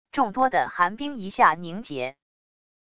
众多的寒冰一下凝结wav音频